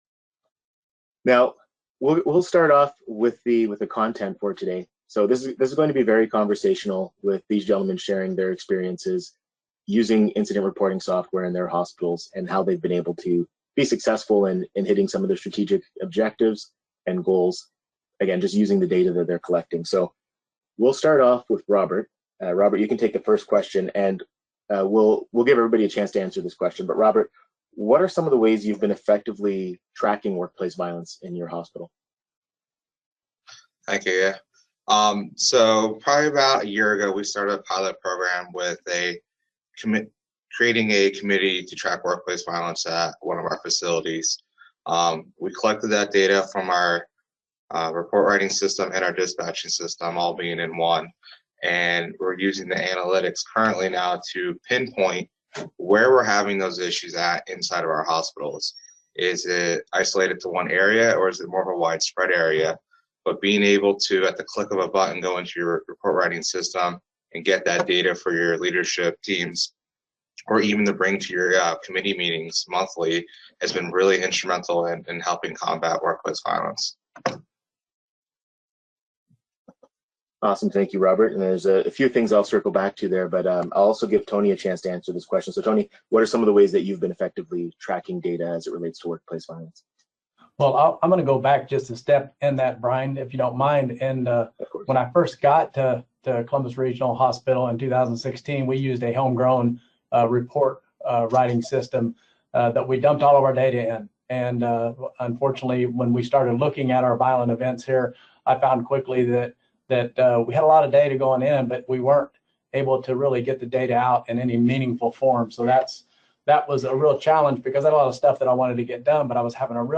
In this podcast, three healthcare professionals discuss their experience using incident reporting software to track and manage workplace violence in hospitals.